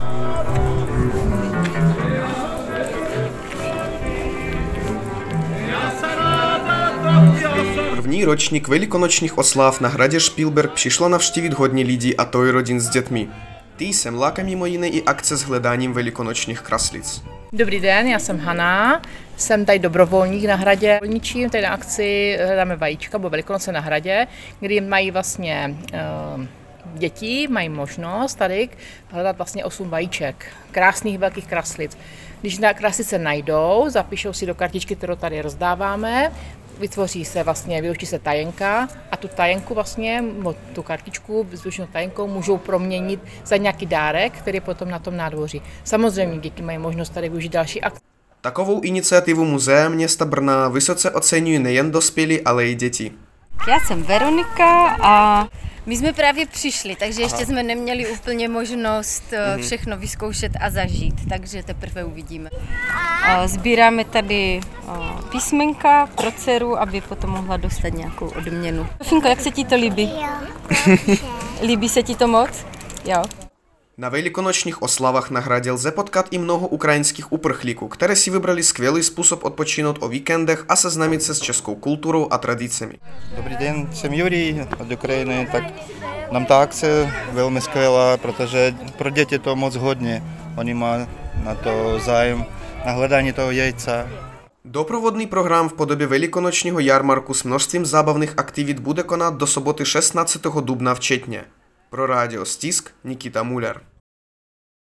Velikonoční oslavy na Špilberku.wav